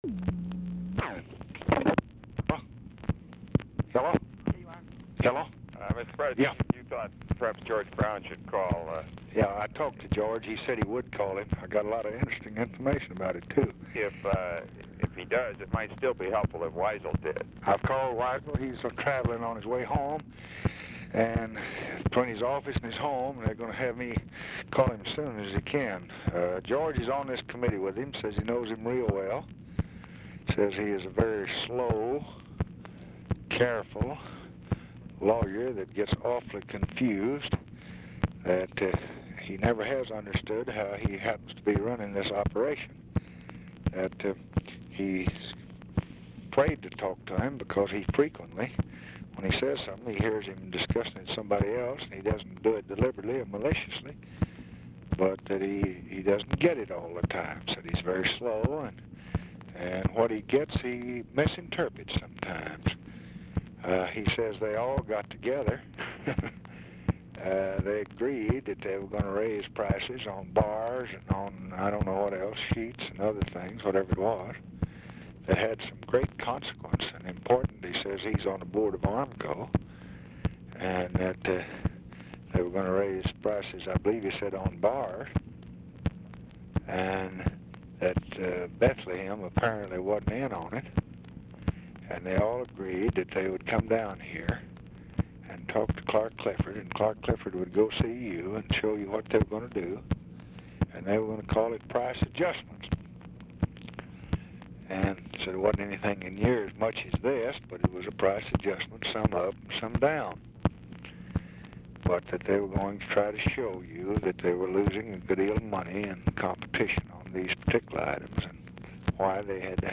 Telephone conversation # 9410, sound recording, LBJ and ROBERT MCNAMARA, 1/4/1966, 6:15PM | Discover LBJ
RECORDING ENDS BEFORE CONVERSATION IS OVER BECAUSE RECORDING IS INTERRUPTED BY FOLLOWING RECORDING
Format Dictation belt